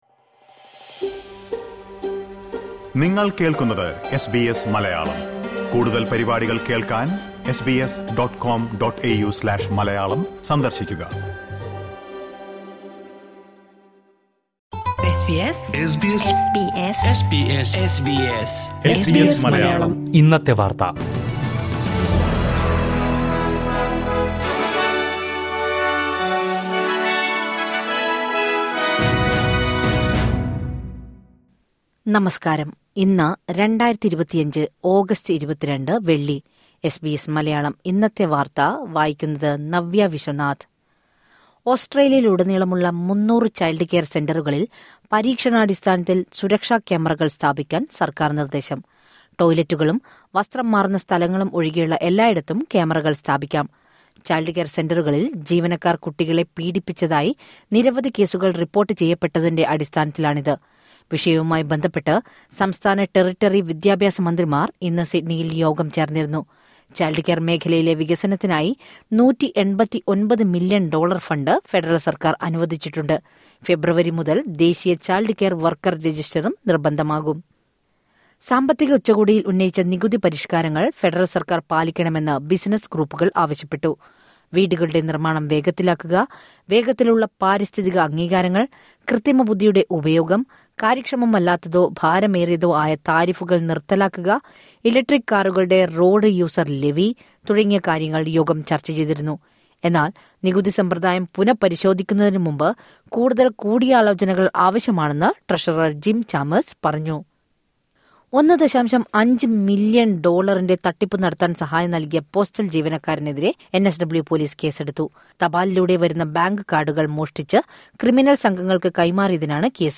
2025 ഓഗസ്റ്റ് 22ലെ ഓസ്ട്രേലിയയിലെ ഏറ്റവും പ്രധാന വാർത്തകൾ കേൾക്കാം...